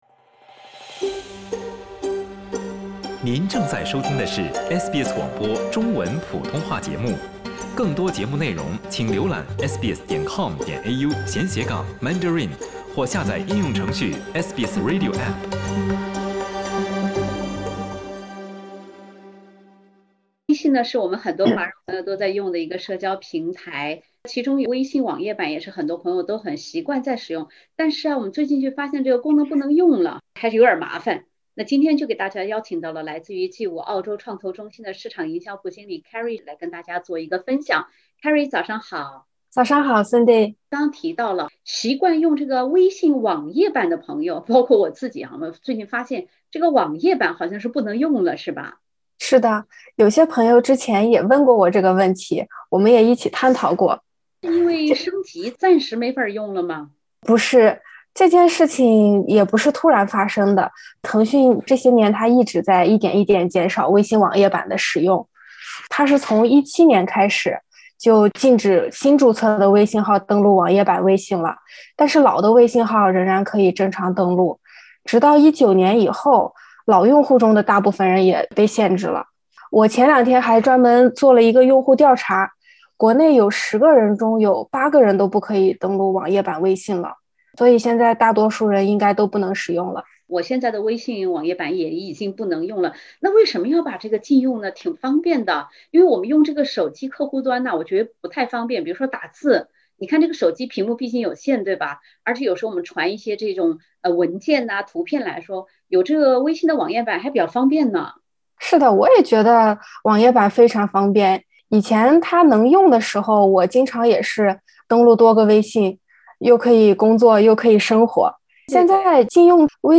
微信网页版突然不能使用，给很多使用它的华人朋友带来不便。（点击封面图片，收听完整采访）